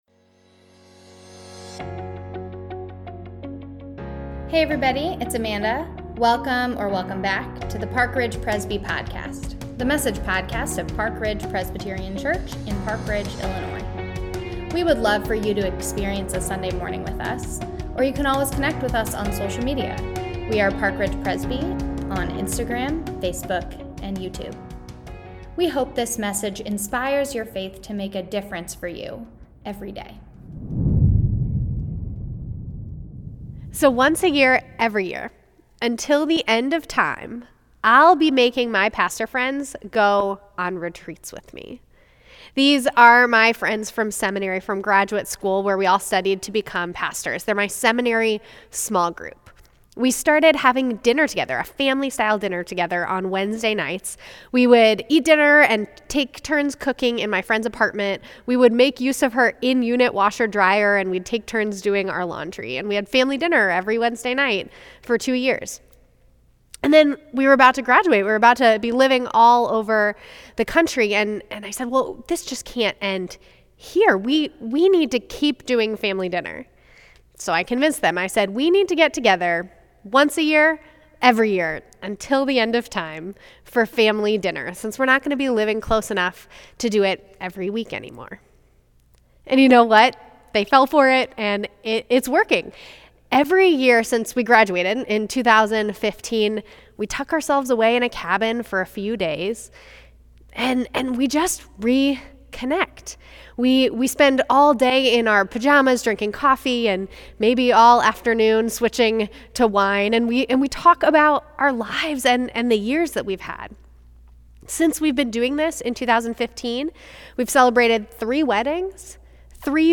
June-6-Sermon.mp3